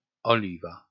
pronunciation) (Latin: Oliva; Kashubian: Òlëwa; German: Oliva) is a northern district of the city of Gdańsk, Poland.